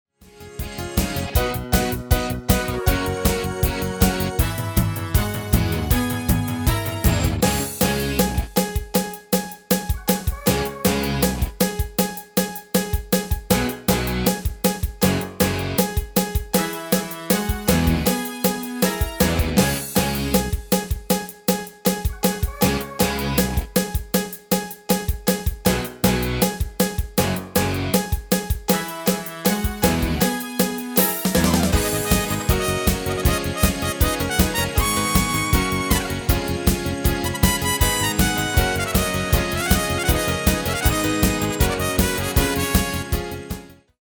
Demo/Koop midifile
Genre: Nederlandse artiesten pop / rock
Toonsoort: G#m
- Géén vocal harmony tracks
Demo's zijn eigen opnames van onze digitale arrangementen.